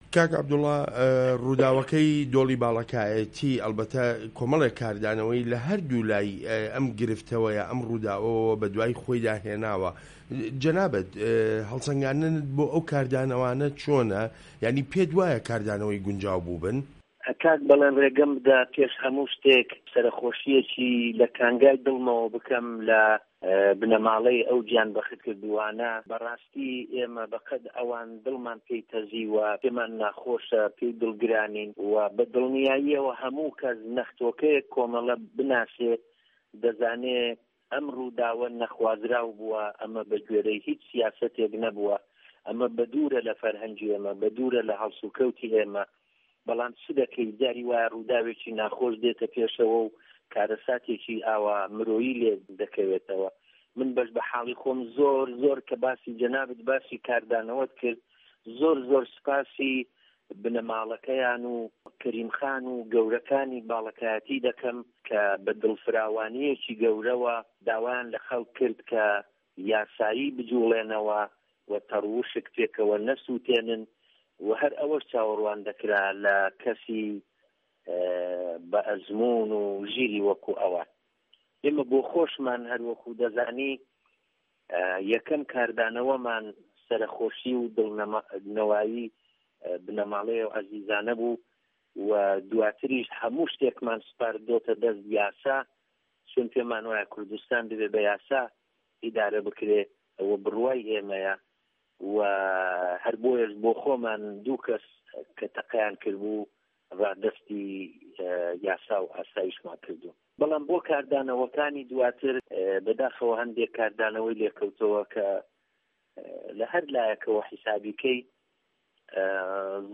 وتووێژ لەگەڵ عەبدوڵای موهتەدی